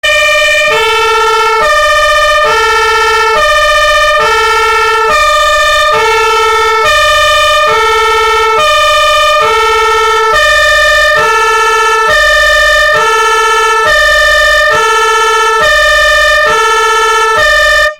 Martinshorn klingelton kostenlos
Kategorien: Soundeffekte